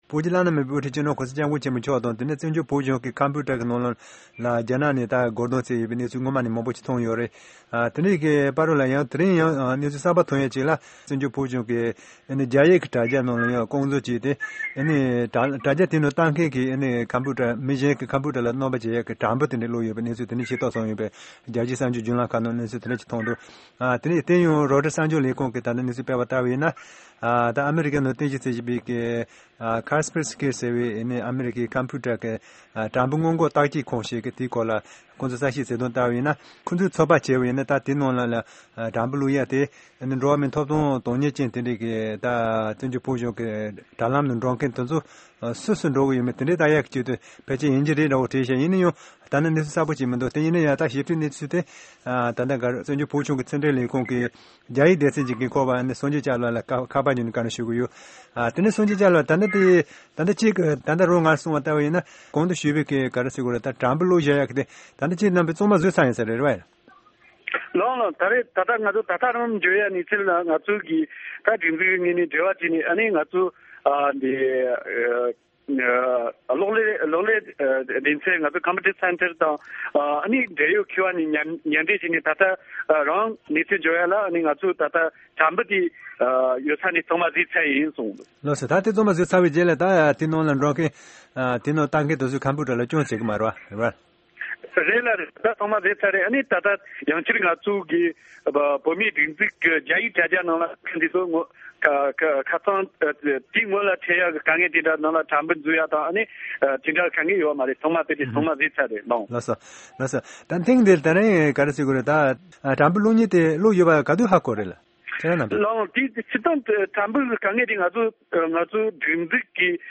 ཕྱོགས་བསྒྲིགས་དང་སྙན་སྒྲོན་ཞུ་གནང་གི་རེད།